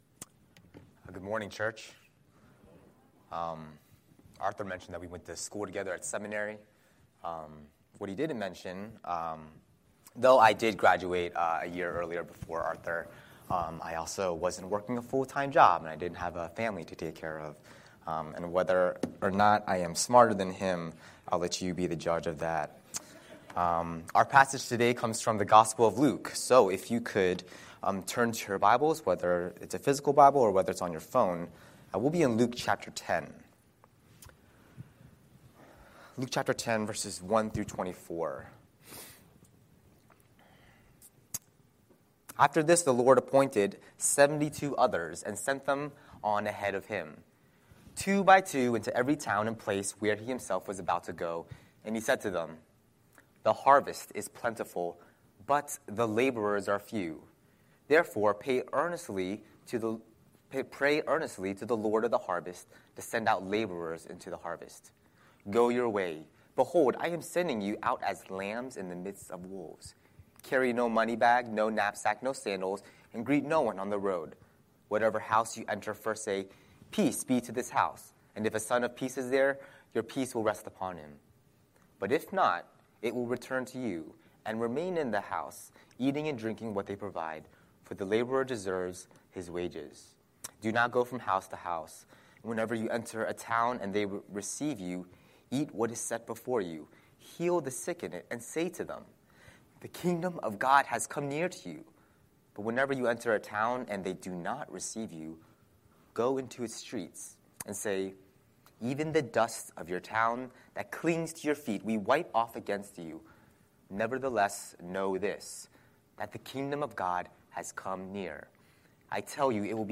Scripture: Luke 10:1-24 Series: Sunday Sermon